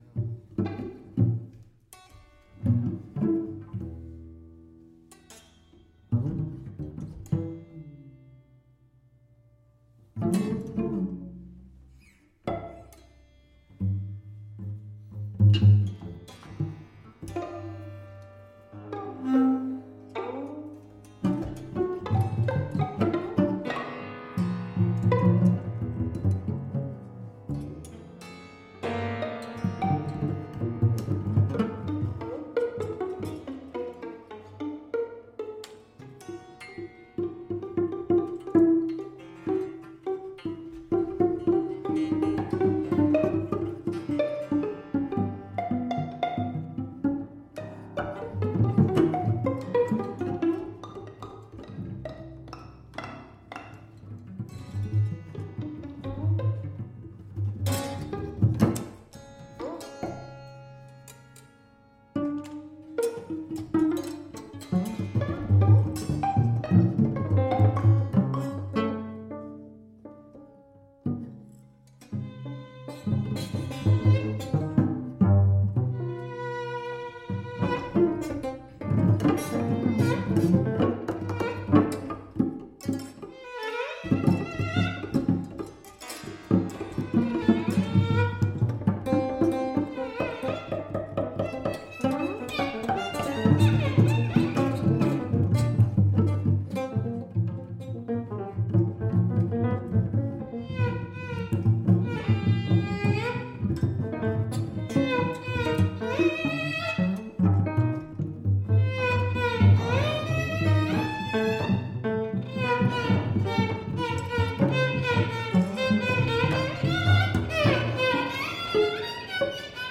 violin
cello
piano and electronics